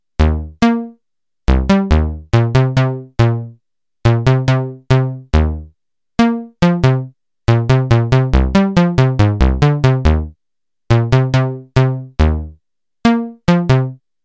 The program I implemented essentially “evolves” a simple musical grammar based on the user’s tastes and renders the sounds using a synthesized bass patch.
The first 3 examples sound similar because they are based on the same grammar derived during one run.